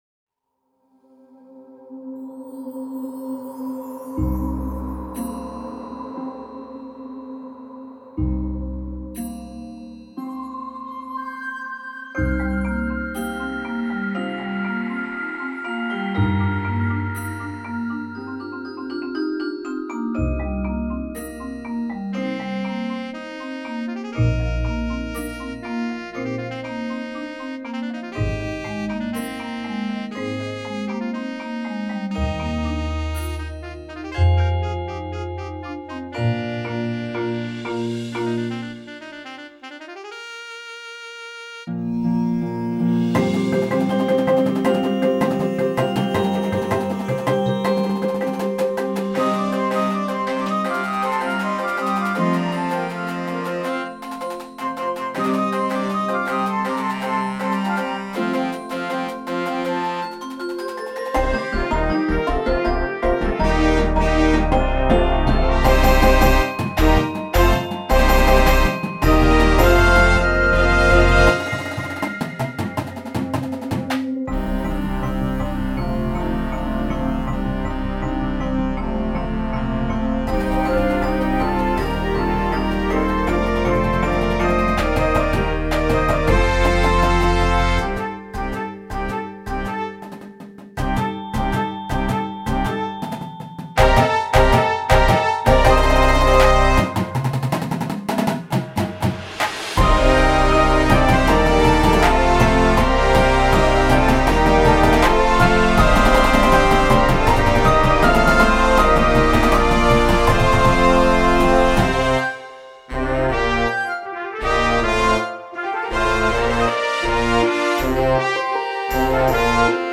Marching Band Shows
Winds
Percussion
• 1-4 Marimbas
• 1 Synth